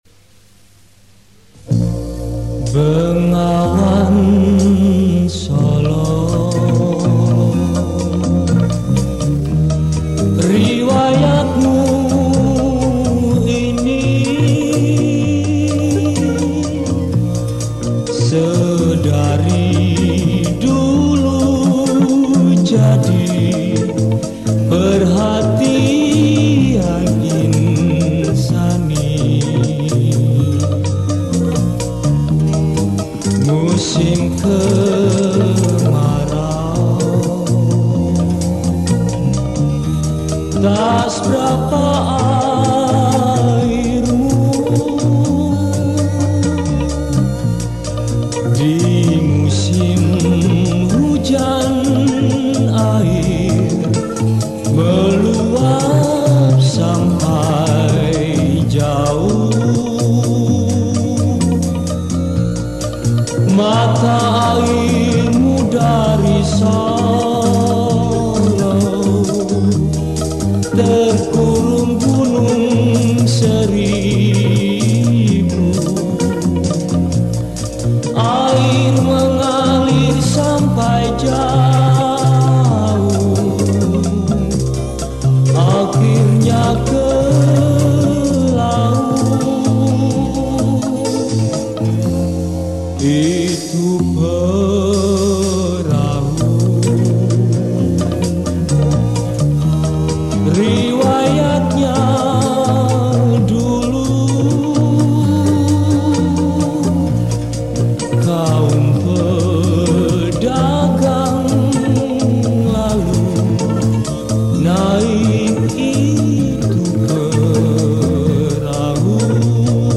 风格来自当地的受葡萄牙音乐影响的Keroncong风民歌。